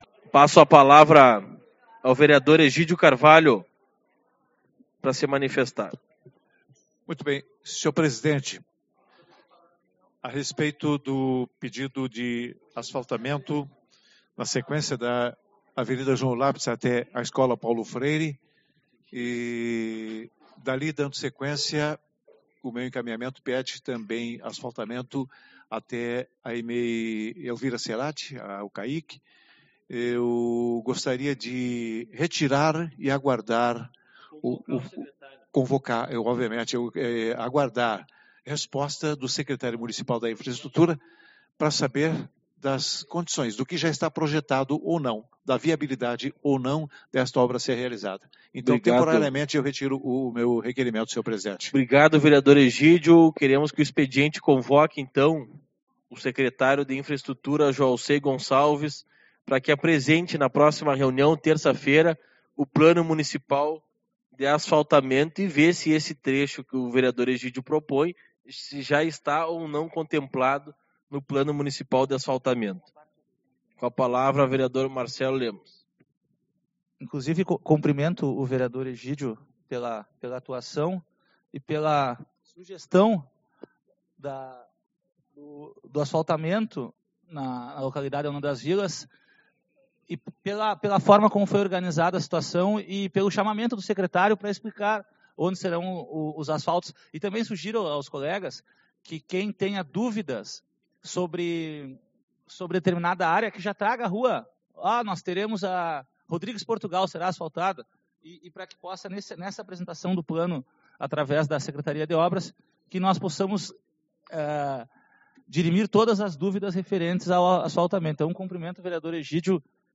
09/12 - Reunião Ordinária